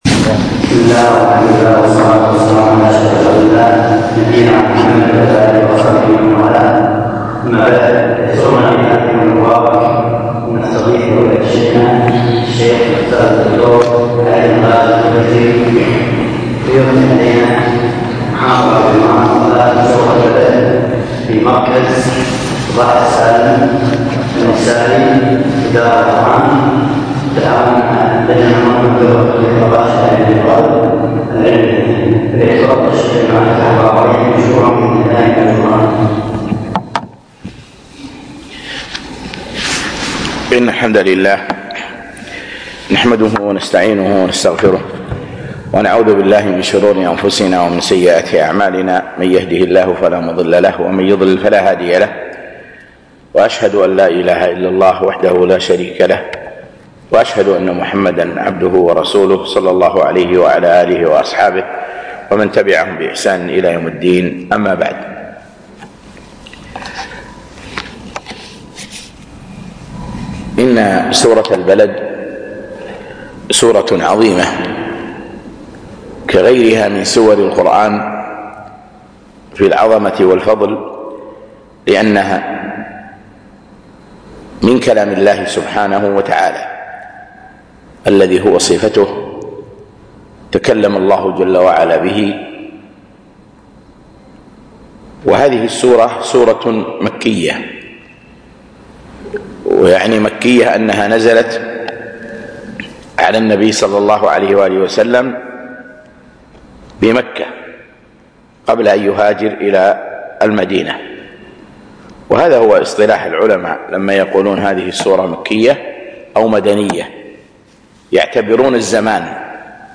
وقفات مع سورة البلد - محاضرة